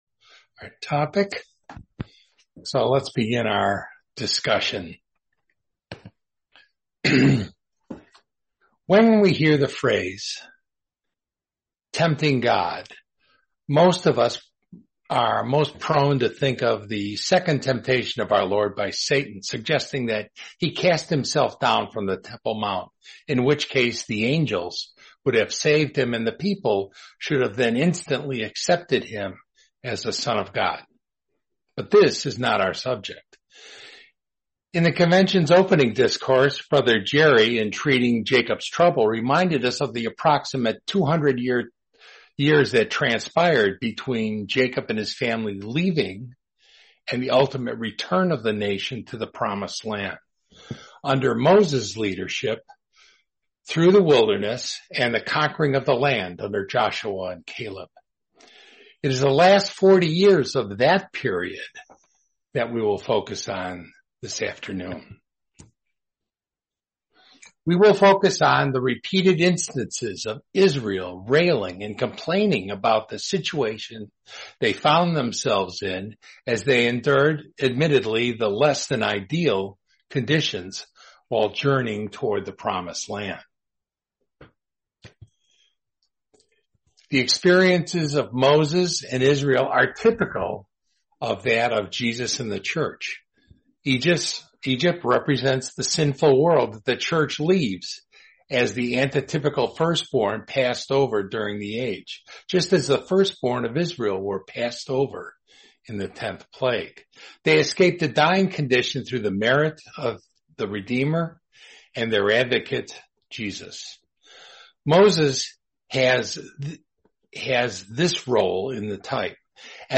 Series: 2026 North Seattle Convention